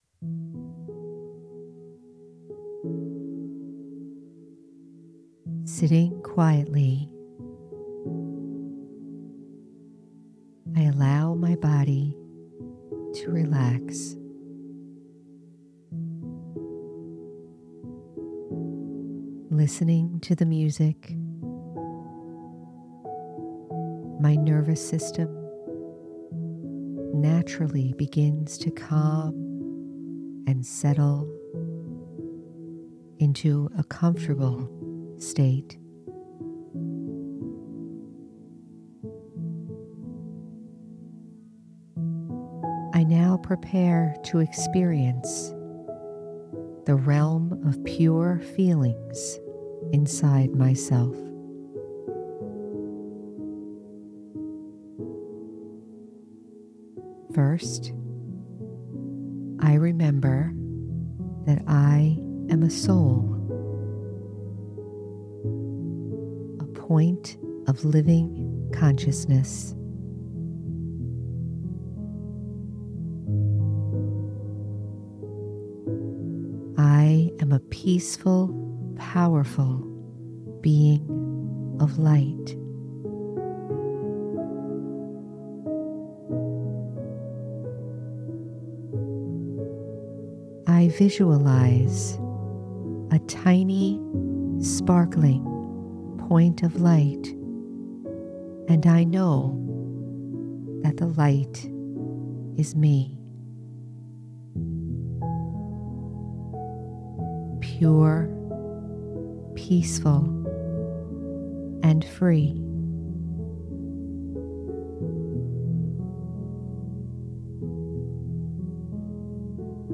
Pure Feelings- Guided Meditation- The Spiritual American- Episode 159